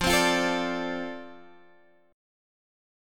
F6 chord